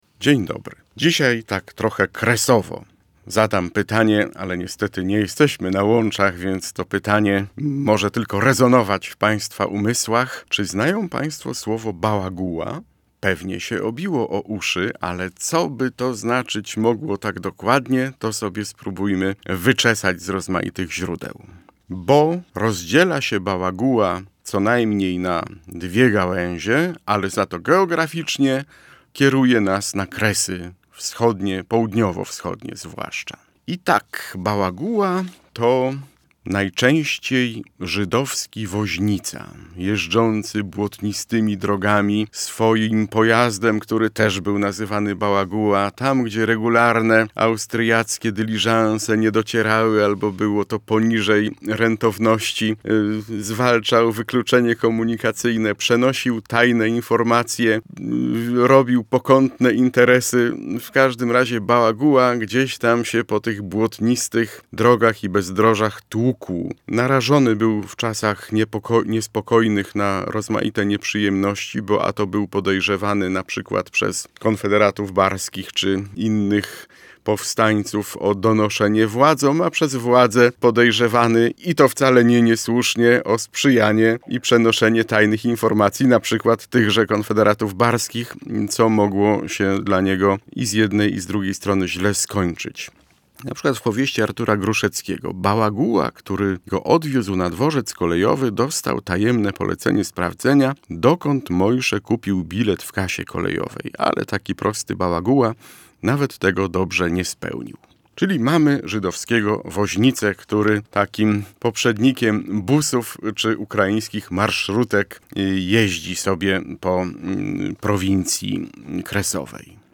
językoznawca przeczesuje słowniki